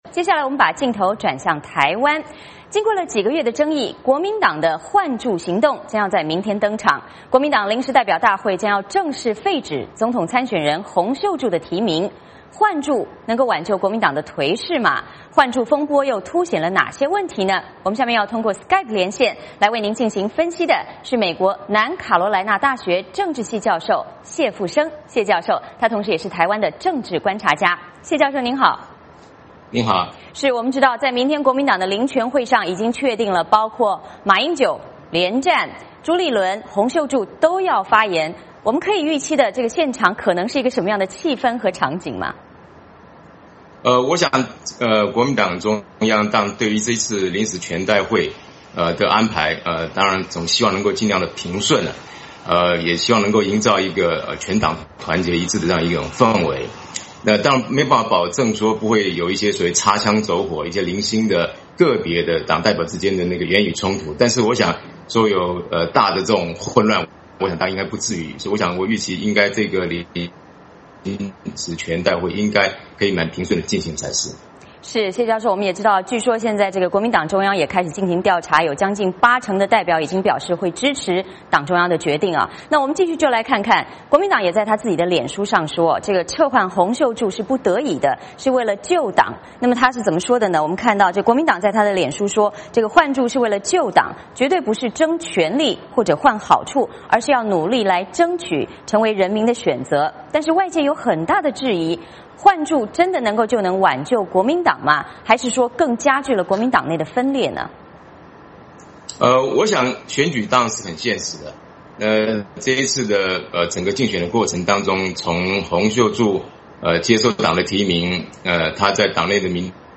下面通过SKYPE连线